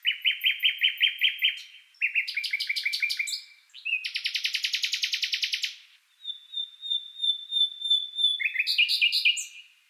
Nachtigall klingelton kostenlos
Kategorien: Tierstimmen